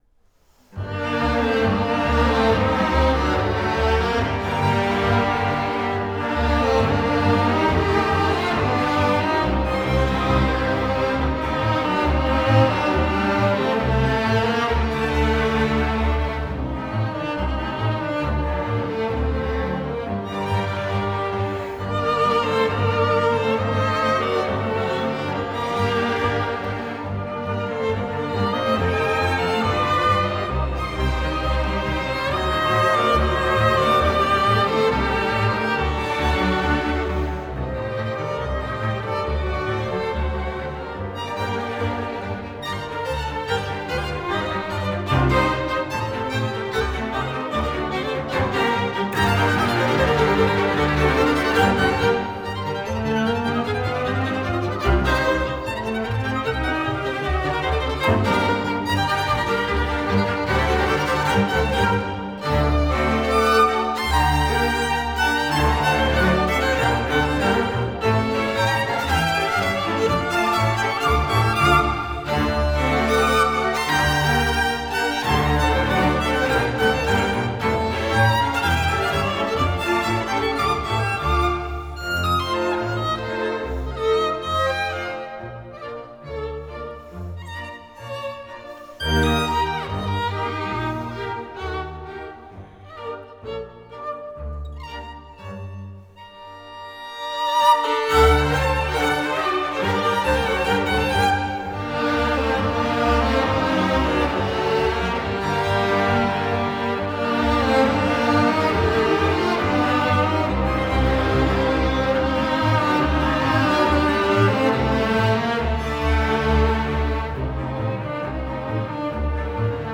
Genre: Classical, Violin